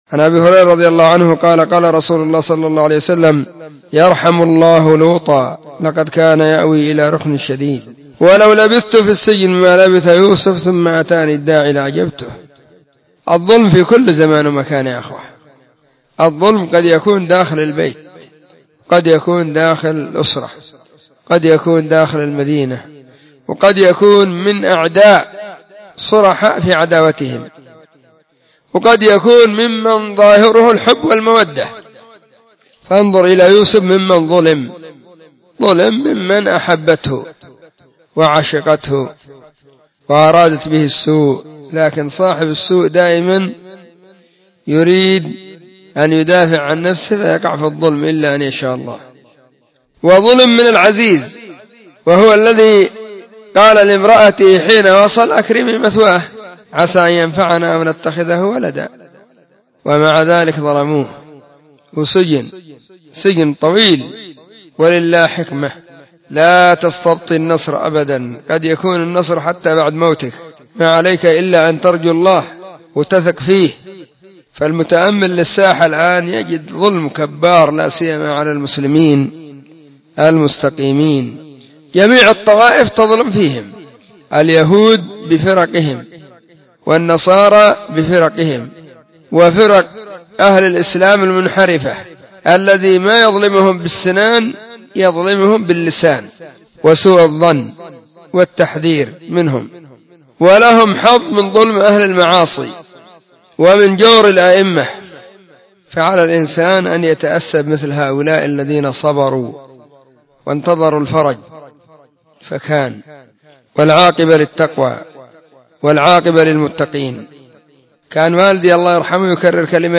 💢نصيحة قيمة بعنوان :شدة المكر بأهل السنة*
📢 مسجد الصحابة بالغيضة, المهرة، اليمن حرسها الله.